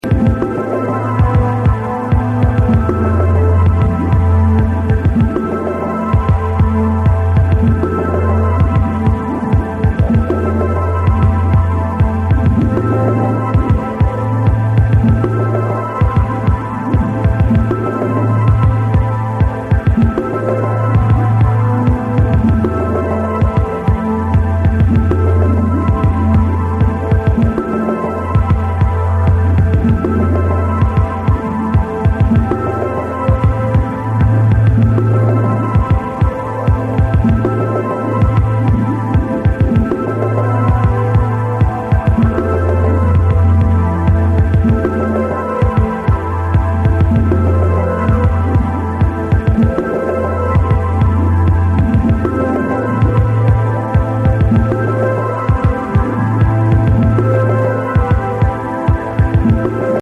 suspenseful and gloomy